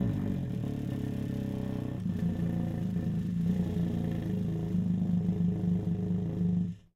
戴伍德小号打击乐" 亚特长H O 1.L - 声音 - 淘声网 - 免费音效素材资源|视频游戏配乐下载
动态表示从pp（非常软）到ff（非常响）。